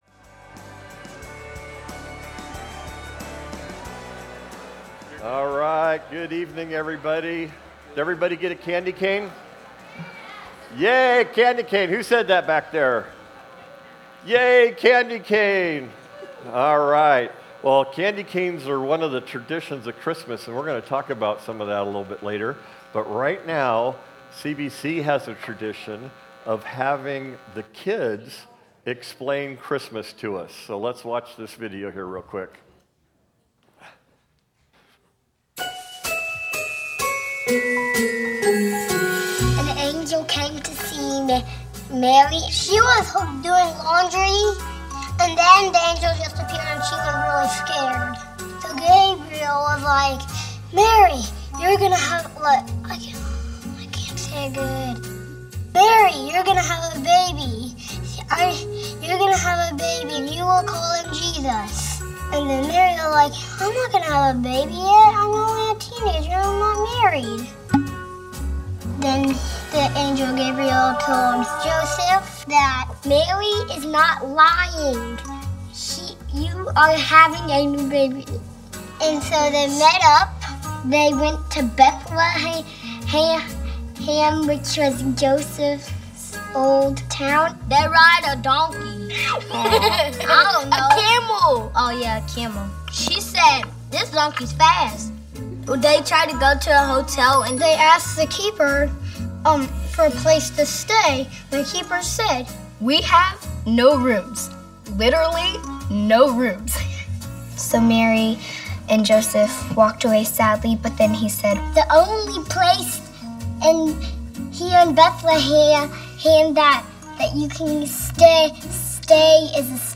Christmas Eve Service 2024